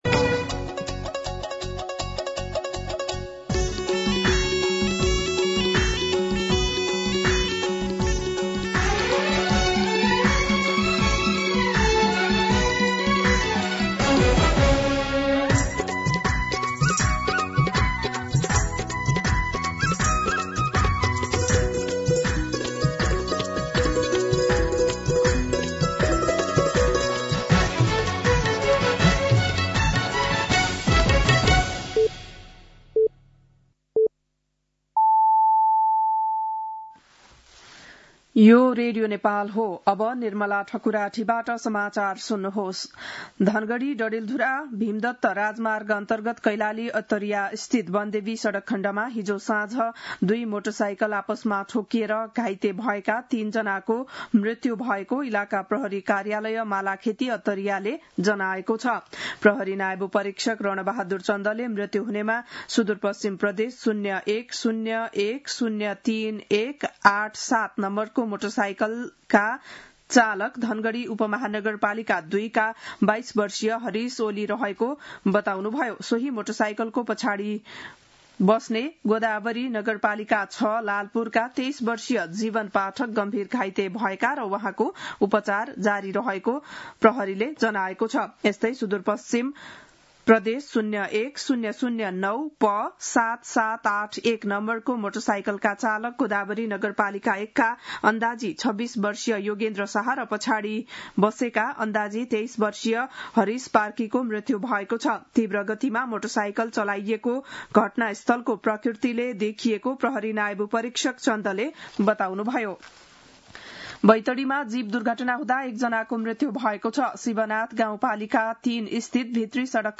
बिहान ११ बजेको नेपाली समाचार : ९ फागुन , २०८२
11-am-Nepali-News-6.mp3